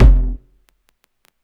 KICK 3.wav